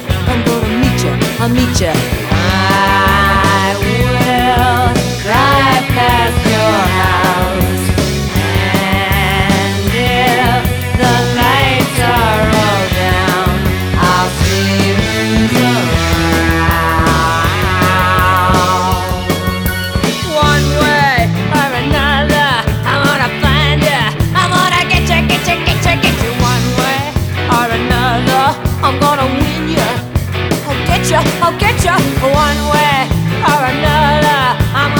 Жанр: Рок
# Rock